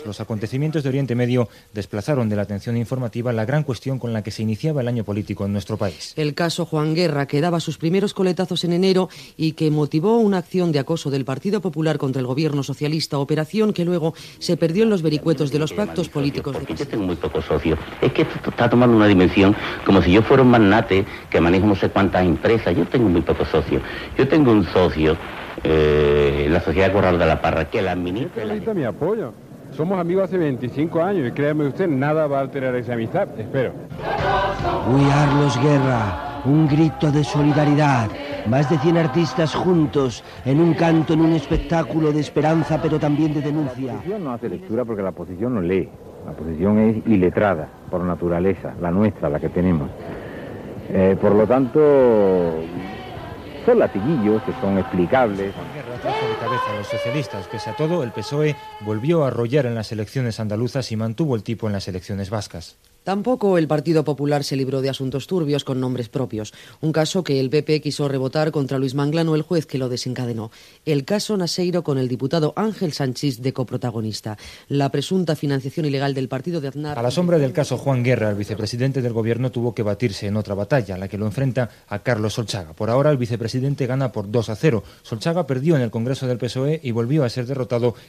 "Los sonidos del 1990" resum informatiu de l'any.